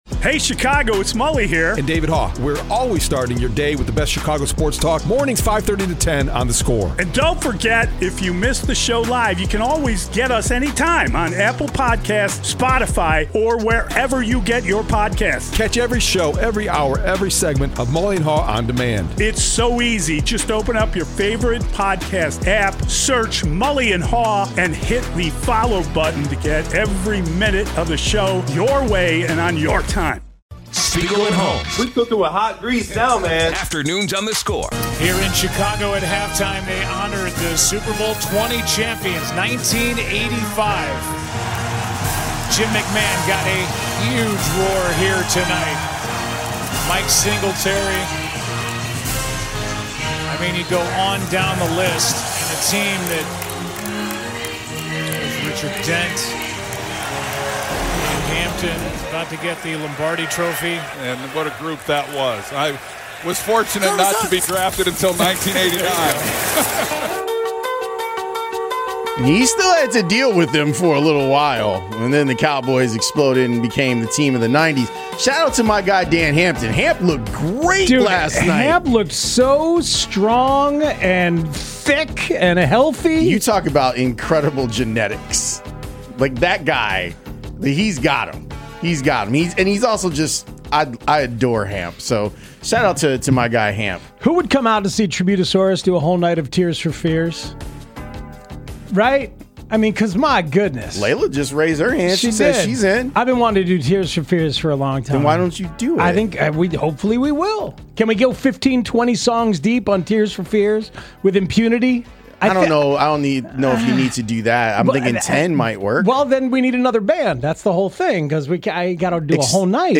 Callers sound off on Caleb Williams & the Bears